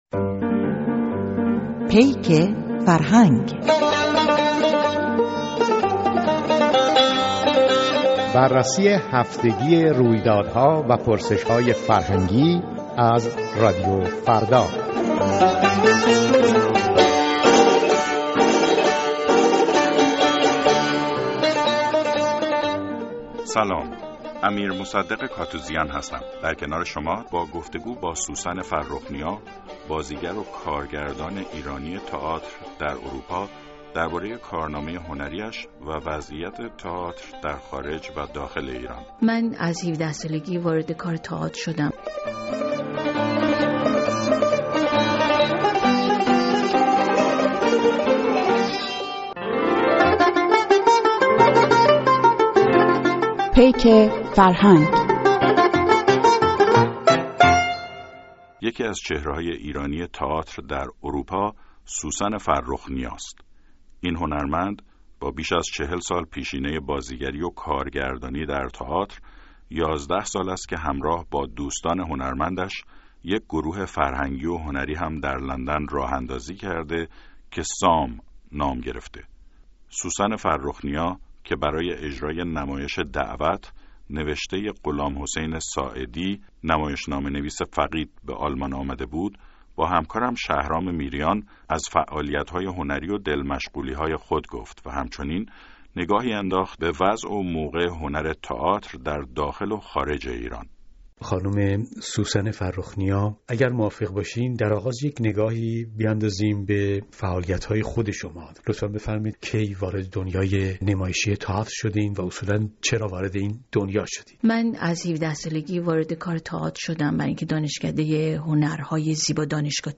گفت‌وگوی پیک فرهنگ